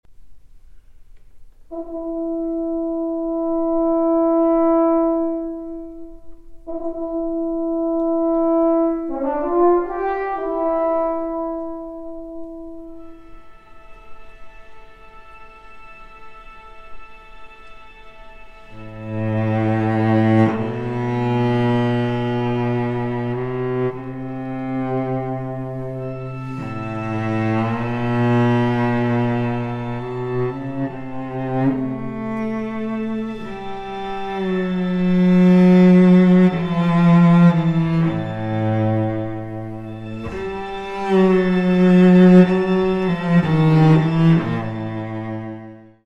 cello
Recorded in the Presence of the Composer